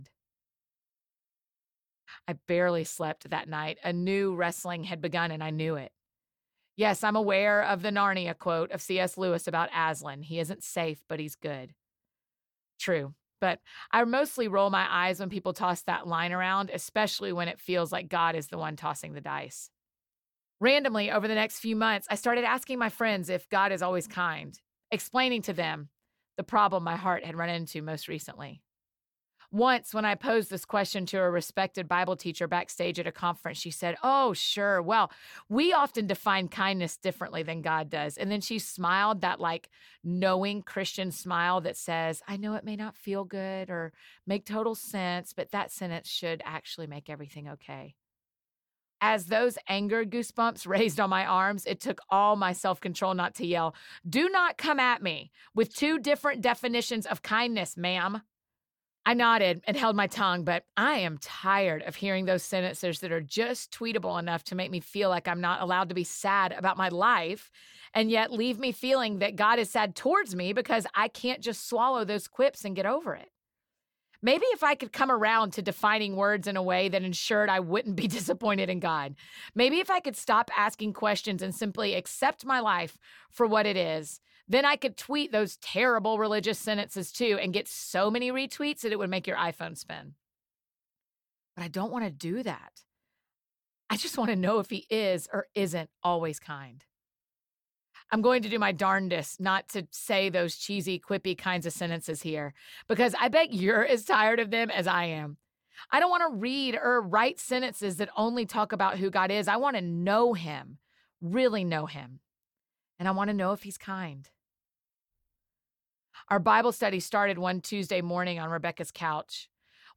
Remember God Audiobook